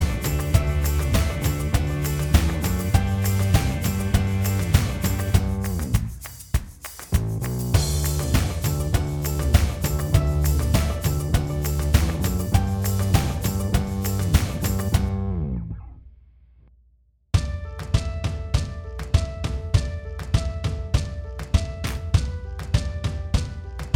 Minus All Guitars Indie / Alternative 3:13 Buy £1.50